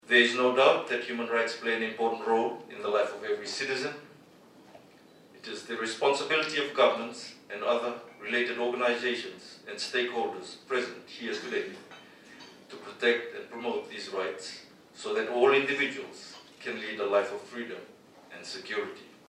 Acting Director of Public Prosecutions, David Toganivalu.
During his opening address, Toganivalu acknowledged that most lawyers are well-versed in the Bill of Rights enshrined within the Constitution.